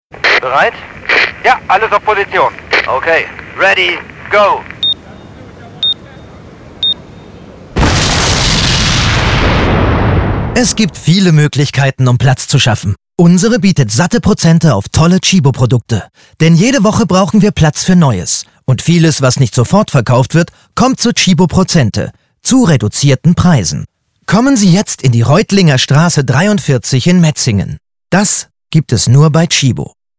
sehr variabel
Mittel plus (35-65)
Commercial (Werbung)